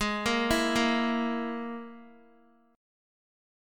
Absus2#5 Chord